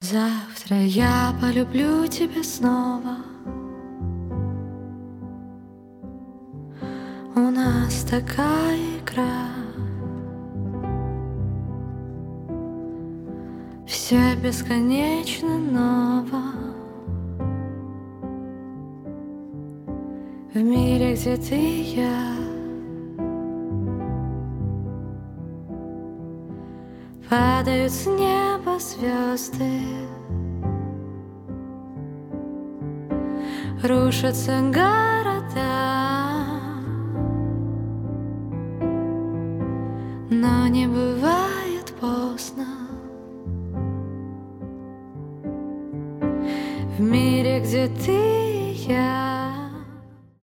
спокойные , романтические
поп